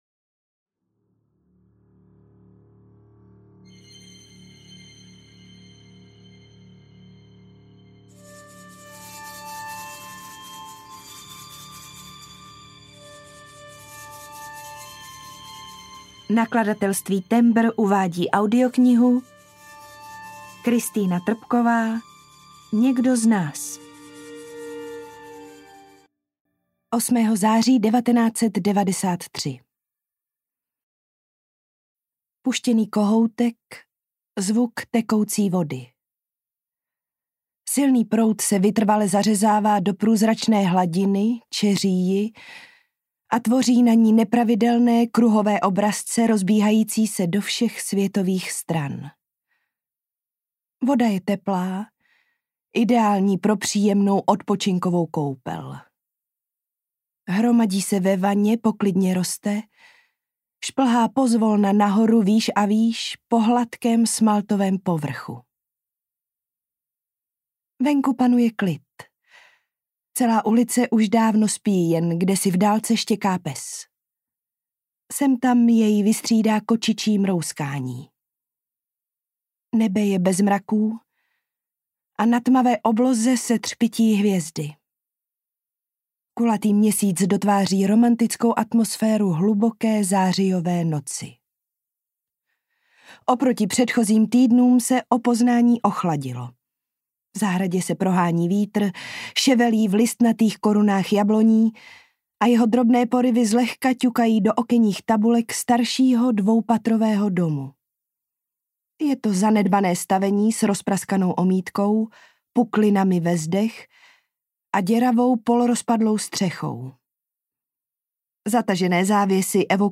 Někdo z nás audiokniha
Ukázka z knihy